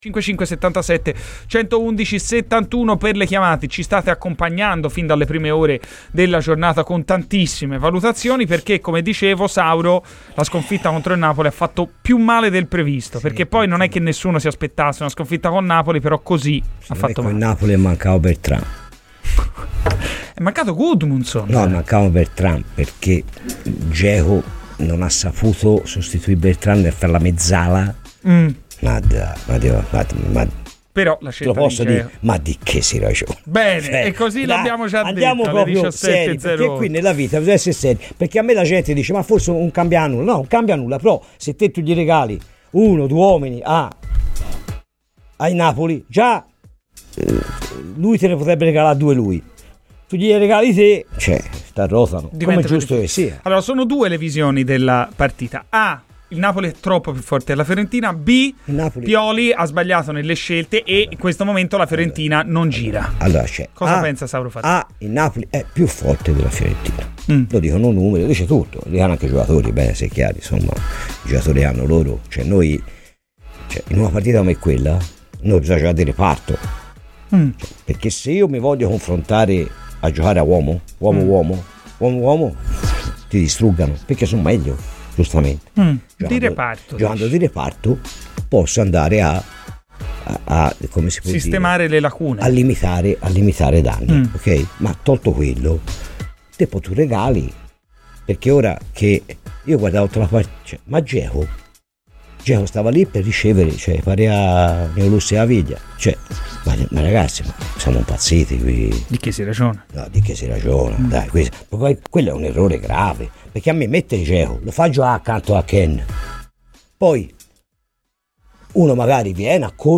Radio FirenzeViola